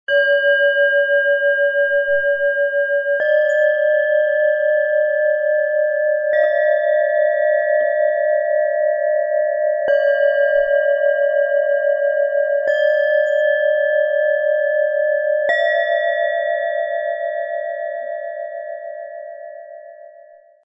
Ruhe, Freude und lichte Klarheit - kleines Klangschalen Set aus 3 Klangschalen, Ø 10,2 - 10,7 cm, 0,82 kg
Die drei Schalen begleiten den Klang von unten nach oben - von entspannender Ruhe über fröhliche Leichtigkeit bis hin zu einer lichten, offenen Klangqualität.
Die drei Klangschalen dieses Sets besitzen eine ruhige, freundliche und gut kontrollierbare Schwingung.
Der Klang wirkt nicht schwer und nicht aufrührend, sondern leicht, bewegend und ausgleichend.
Der mittlere Ton bringt Bewegung und eine freundliche, fröhliche Qualität in den Klang.
Er wirkt hell, harmonisch und klar und öffnet einen lichten Raum für Präsenz und feine Wahrnehmung.
Mit dem Sound-Player - Jetzt reinhören können Sie den Originalklang genau dieser drei Schalen des Sets in Ruhe anhören.
So erhalten Sie einen authentischen Eindruck von der feinen, freundlichen und lichtvollen Klangabfolge - von Ruhe über Leichtigkeit bis hin zu klarer Präsenz.
Bengalen Klangschale, matt - mit Om-Gravur am Boden innen